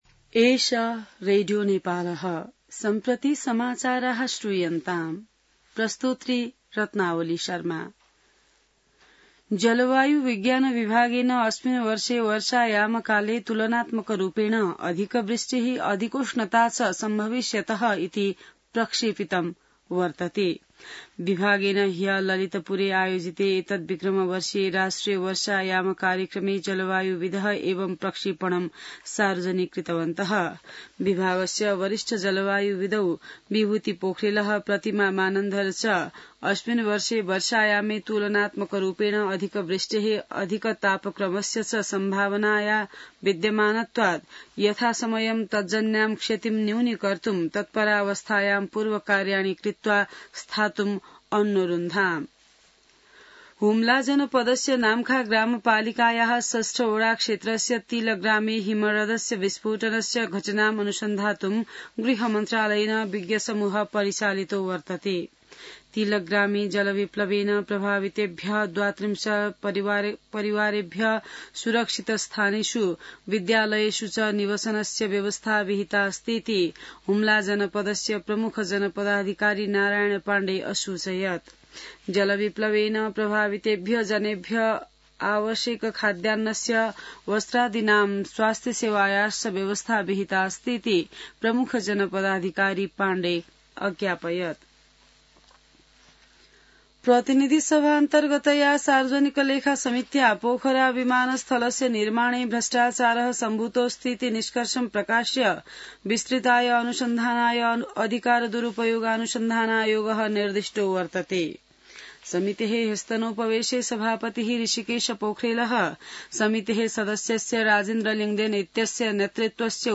संस्कृत समाचार : ८ जेठ , २०८२